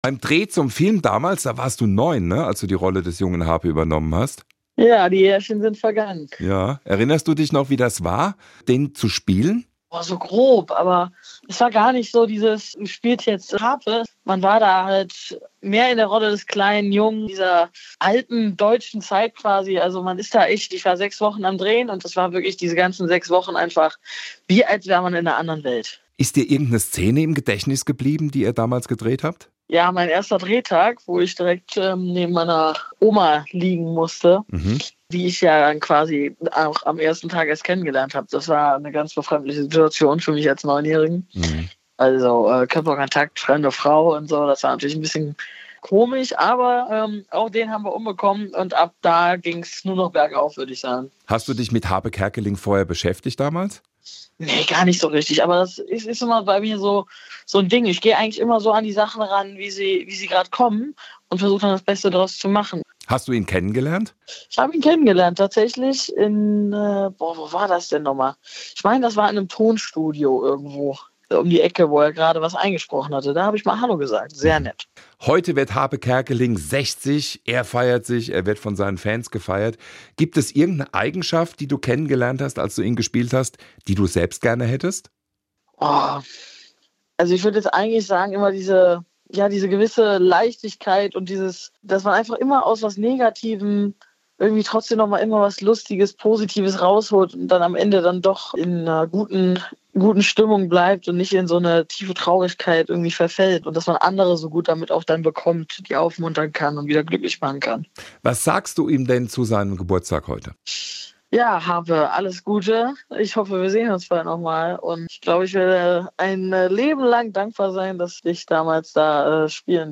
Interview mit
Julius Weckauf, Schauspieler